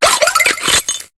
Cri de Sorbouboul dans Pokémon HOME.